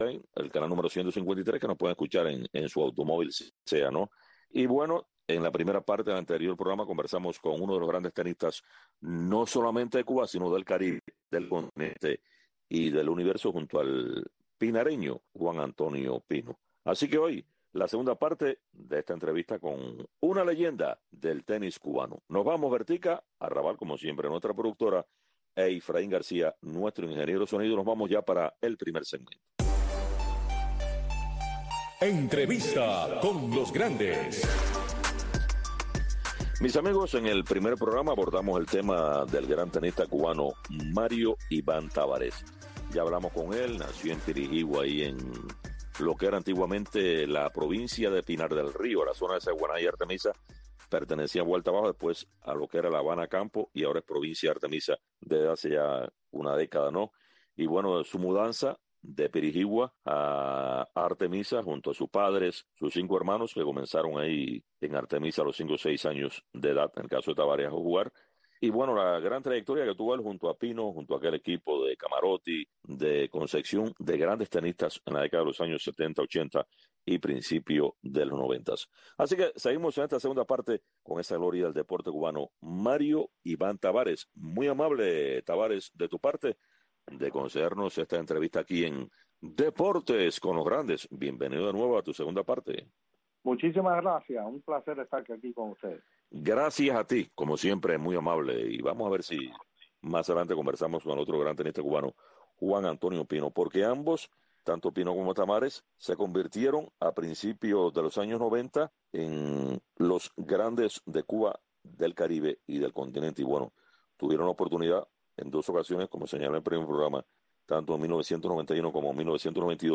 Deportes con los grandes. Un programa de Radio Martí, especializado en entrevistas, comentarios, análisis de los grandes del deporte.